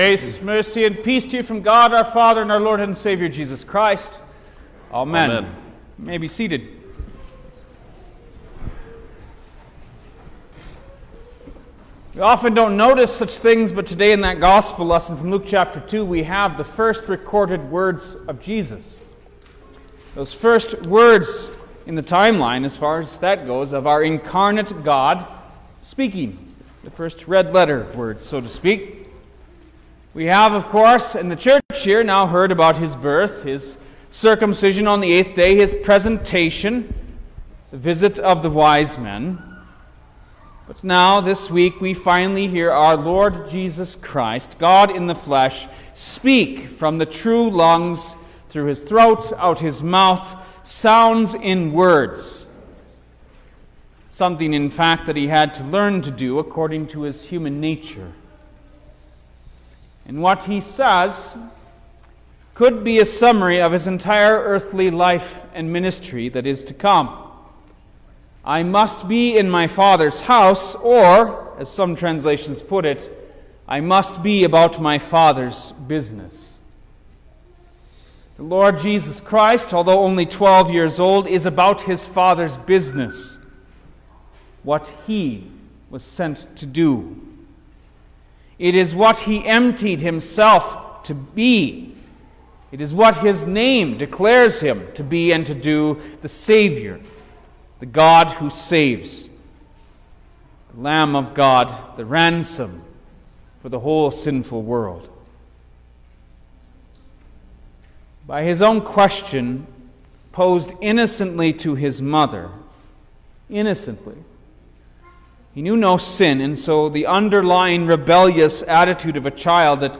January-8_2023_The-First-Sunday-After-Epiphany_Sermon-Stereo.mp3